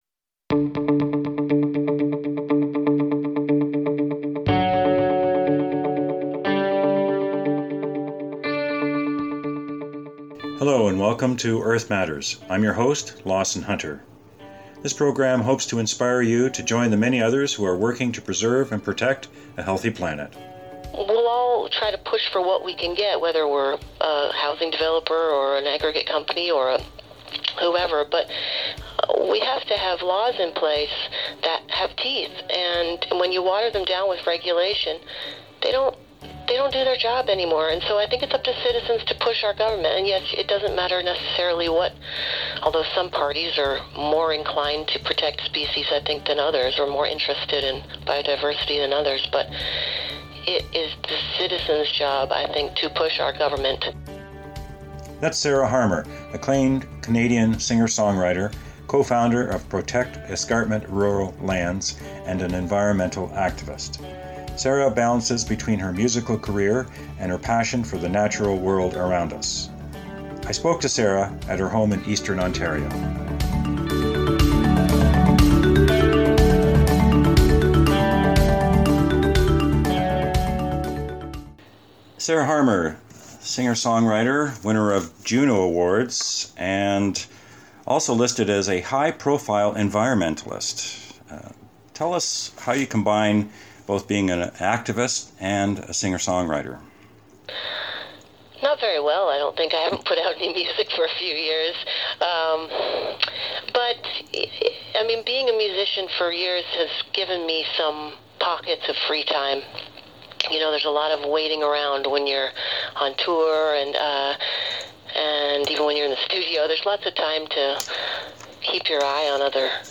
Interview with musician/activist Sarah Harmer